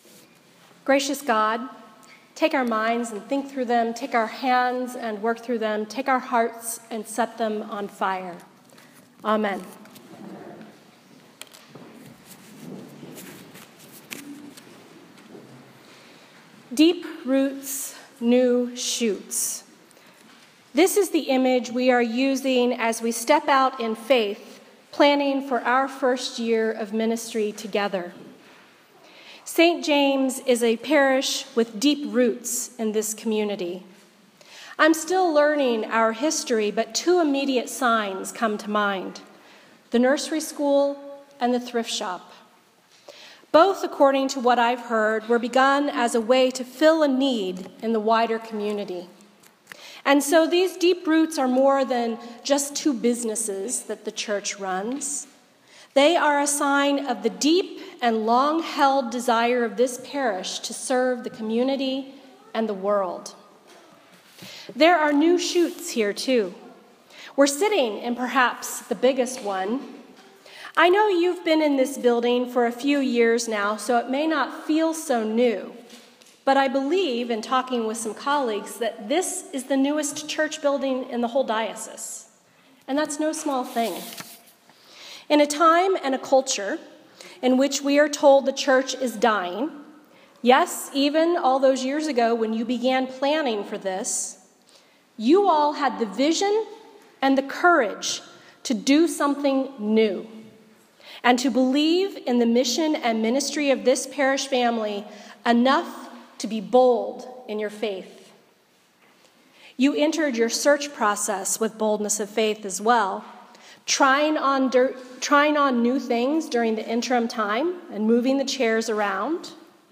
A Sermon for the Twentieth Sunday after Pentecost 2015